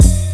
Dre BassHat Layer.wav